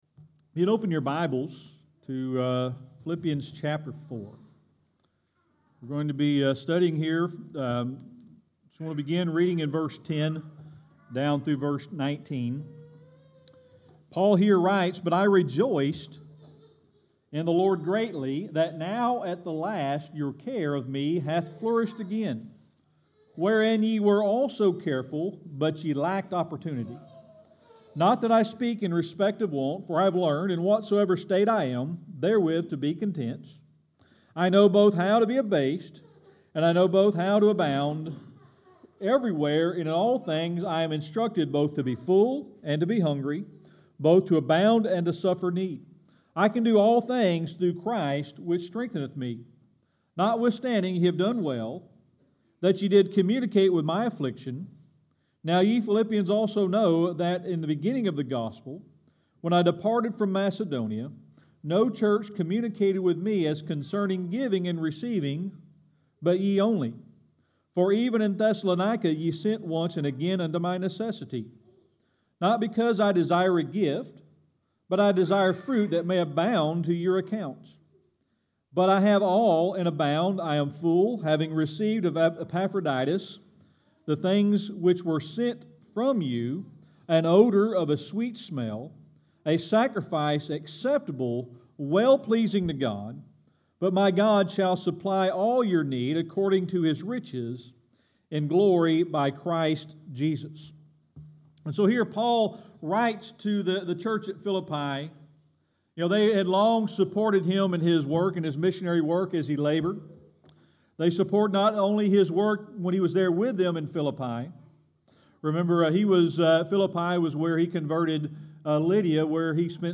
Series: Sermon Archives
Philippians 4:10-19 Service Type: Sunday Morning Worship Paul writes to the church in Philippi.